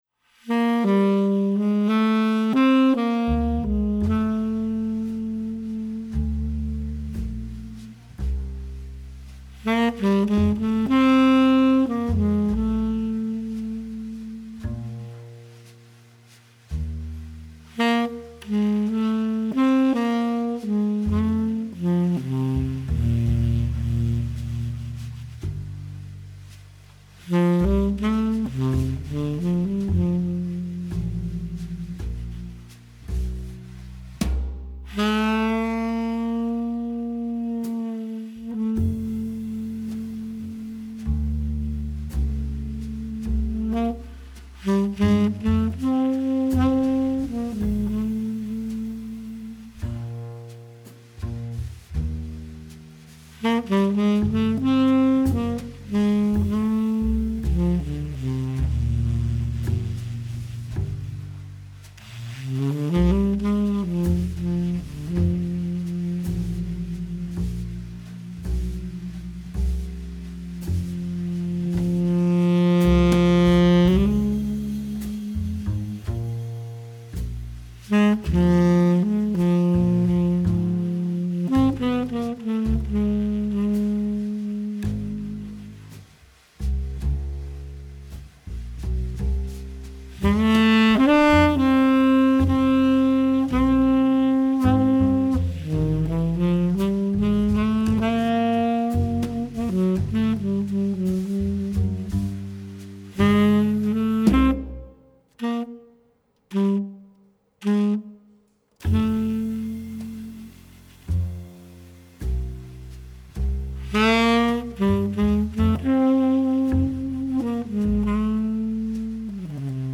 TRIO
and it’s about playing medium tempo’s